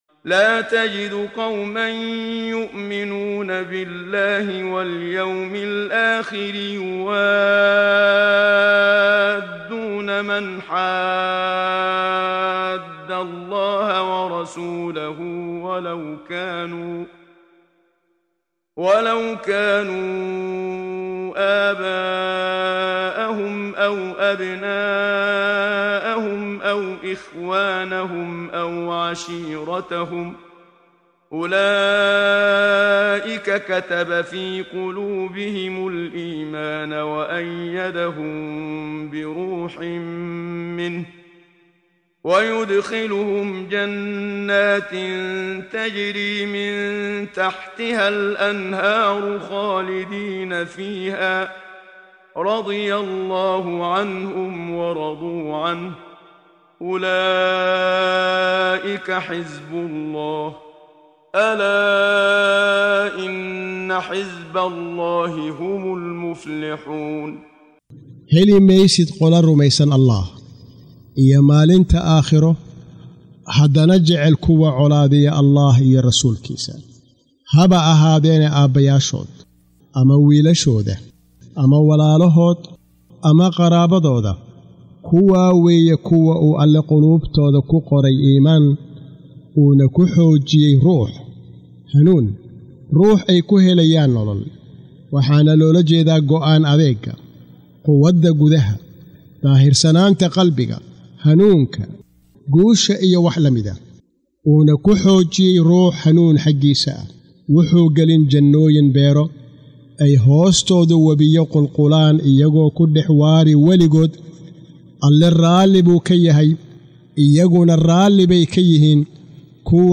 Waa Akhrin Codeed Af Soomaali ah ee Macaanida Suuradda Al-Mujaadilah ( doodeysa ) oo u kala Qaybsan Aayado ahaan ayna la Socoto Akhrinta Qaariga Sheekh Muxammad Siddiiq Al-Manshaawi.